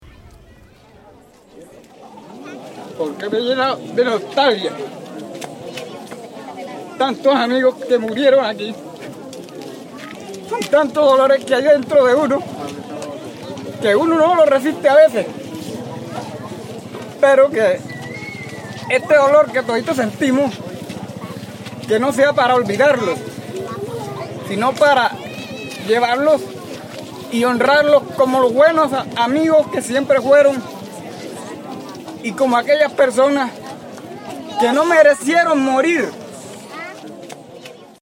Un recorrido por la memoria que evoca el dolor, la resistencia, la esperanza y las reivindicaciones de las víctimas que ahora alzan su voz frente a la historia que las silenció. Su memoria se narra a partir de sonidos propios del folclore montemariano, como el vallenato y las décimas, y también desde la poesía y los testimonios que interpretan los relatos de los victimarios.